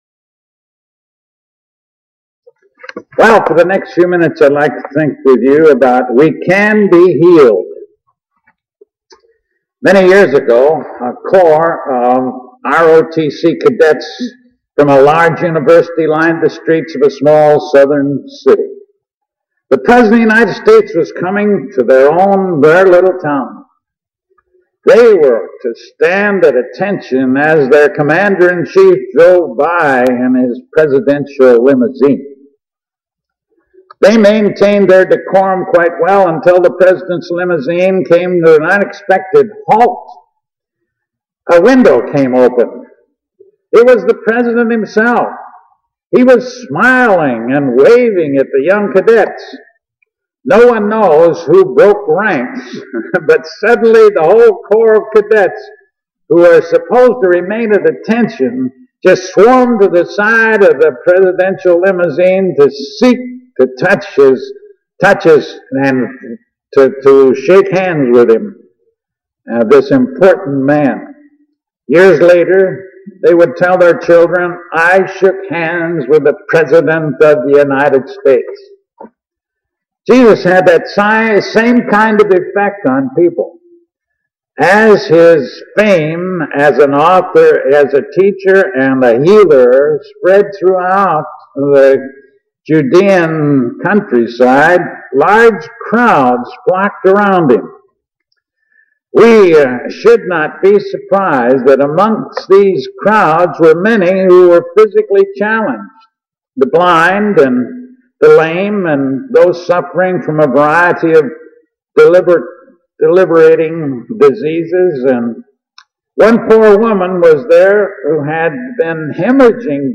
Mark 5: 25-34 We Can Be Healed Filed Under: All Christian Sermons , Spiritural Healing